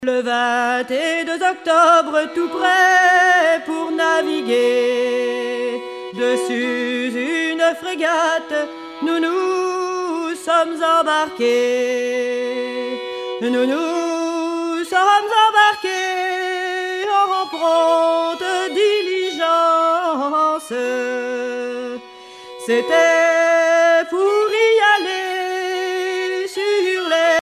circonstance : maritimes
Genre strophique
Pièce musicale éditée